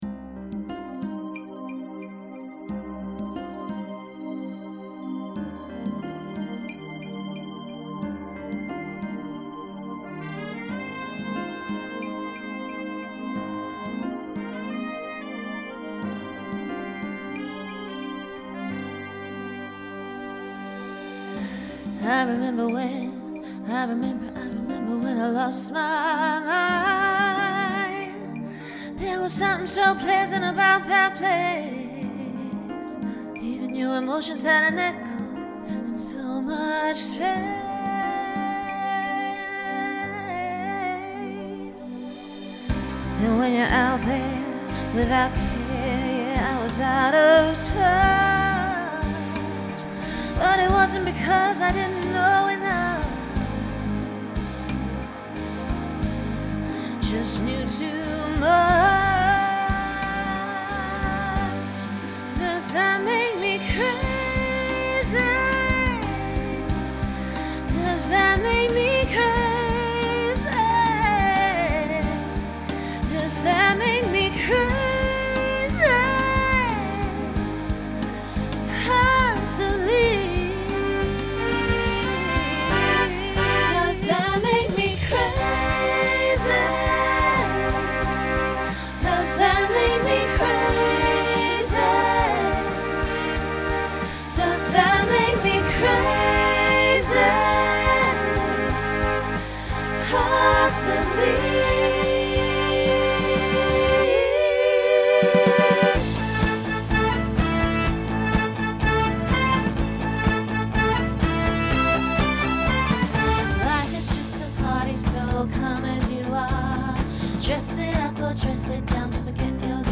Genre Pop/Dance Instrumental combo
Solo Feature Voicing SSA